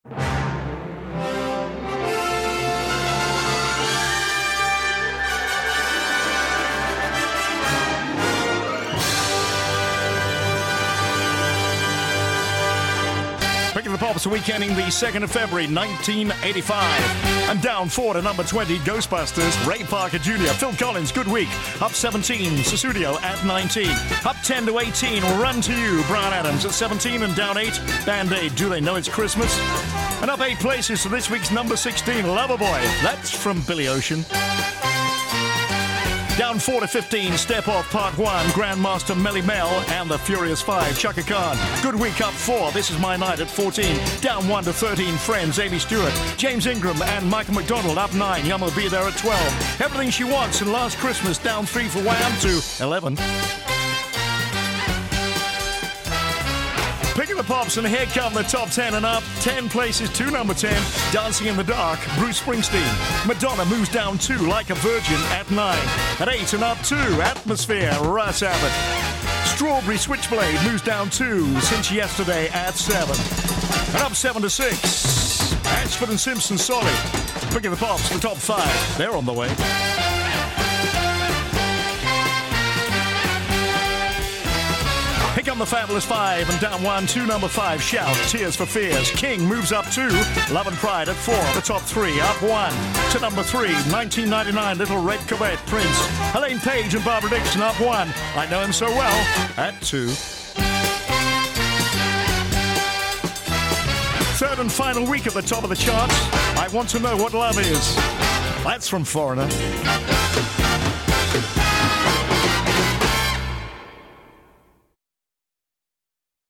Here is the top 20 UK chart rundown from the week ending 2nd February 1985, as broadcast on Pick of the Pops.